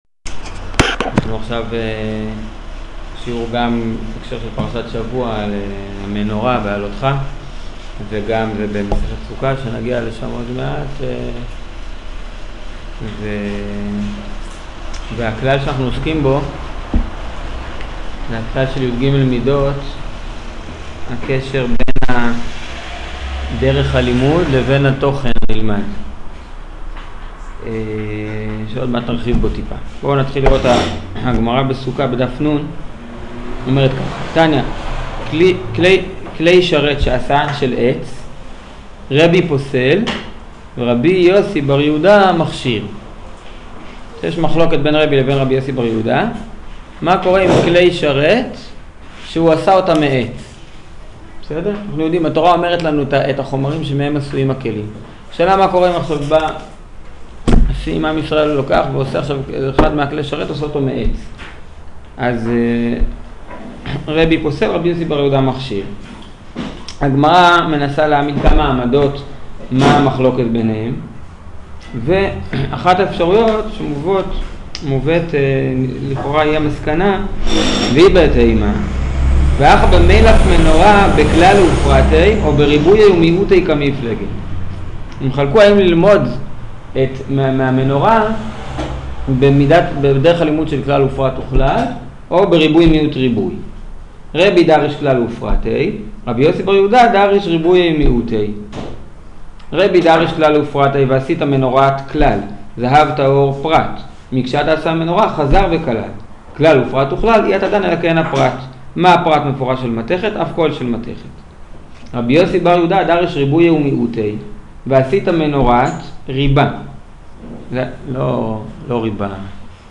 שיעור המנורה